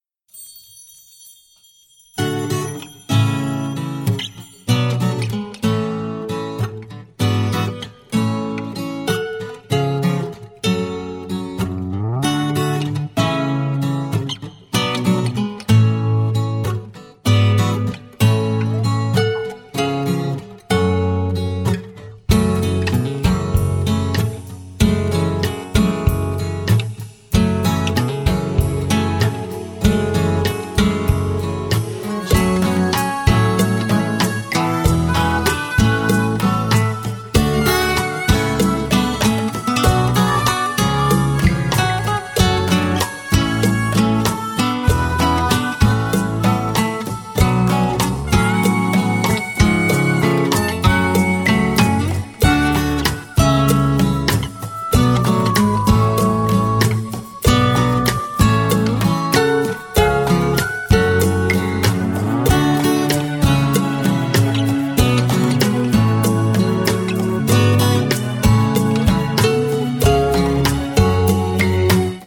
• Качество: 179, Stereo
гитара
грустные
без слов
инструментальные
проигрыш на гитаре